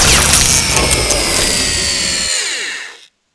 Index of /cstrike/sound/turret